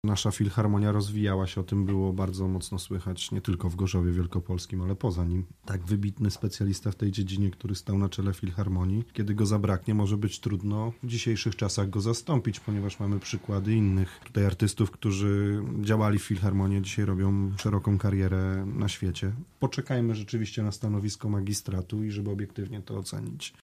Z kolei Robert Anacki, radny klubu Prawa i Sprawiedliwości wskazuje